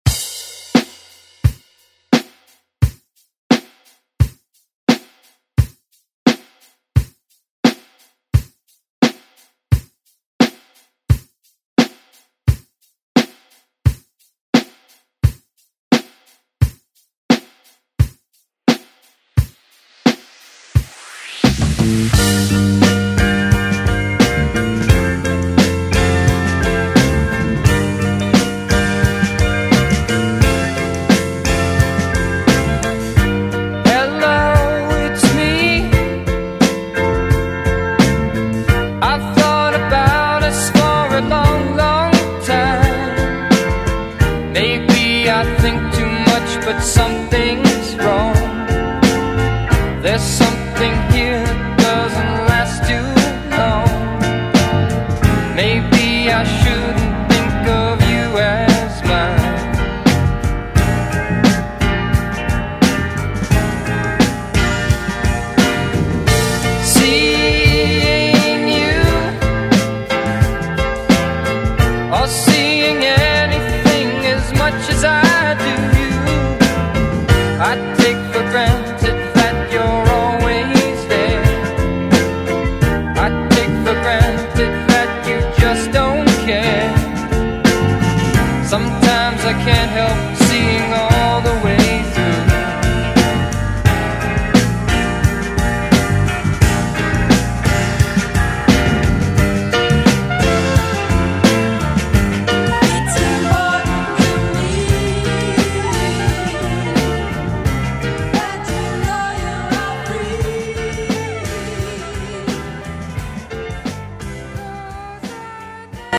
Throwback Vocal Pop Music Extended ReDrum Clean 87 bpm
BPM: 87 Time